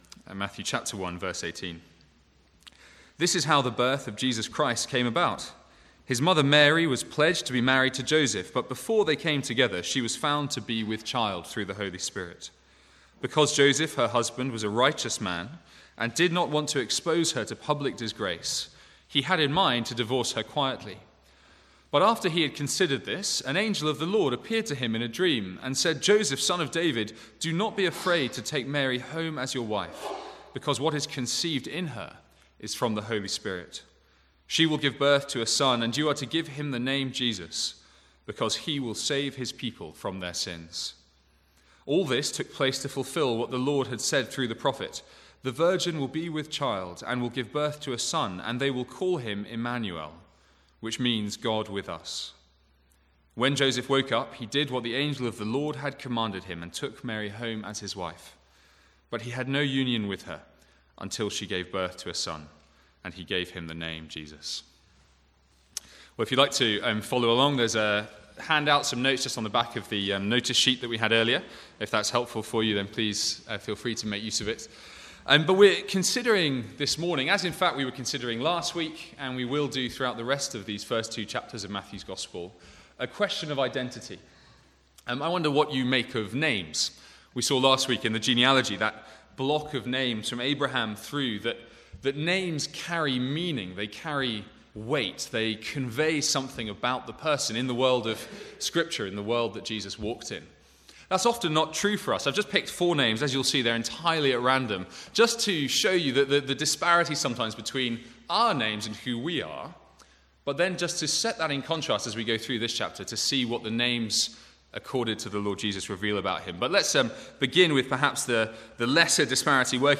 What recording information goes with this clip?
From the Sunday morning Advent series 2014.